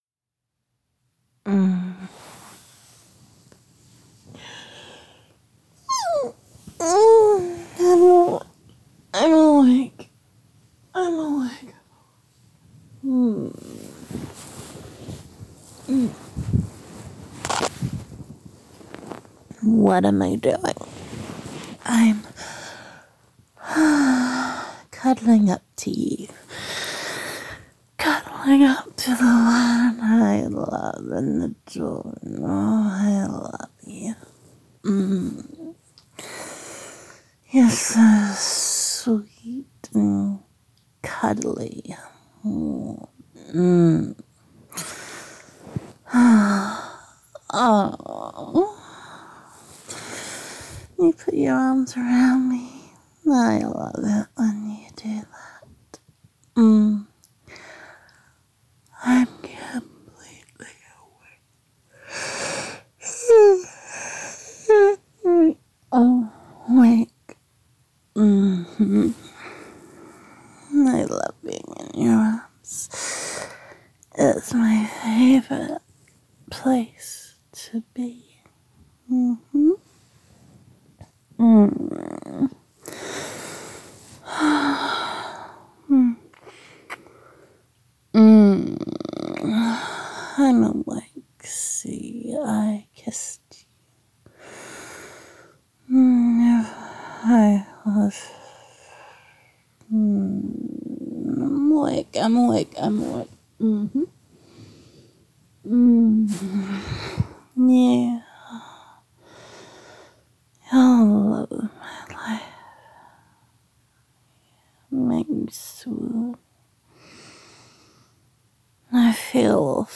[F4A] Snoozing Before the Alarm
[Sheet Sounds][Sleepy Voiced][Adorable Yawns]
[Girlfriend Roleplay]